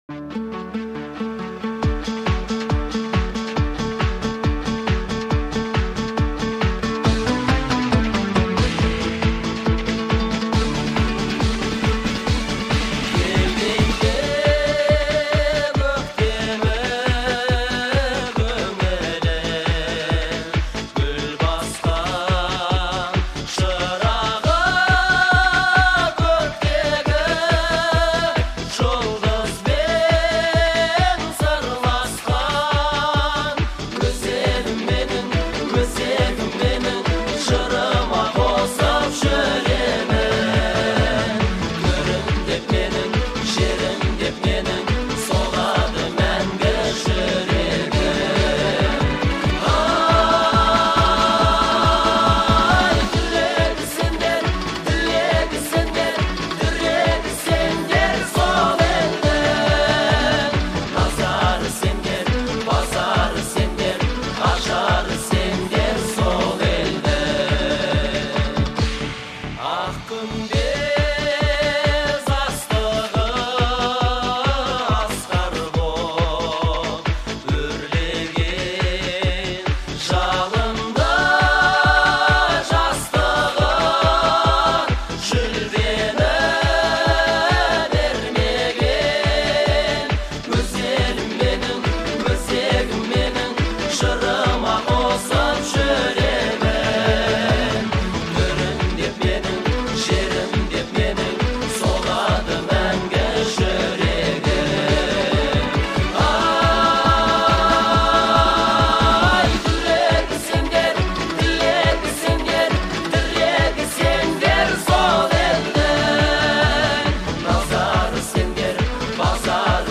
Настроение композиции — патриотичное и вдохновляющее.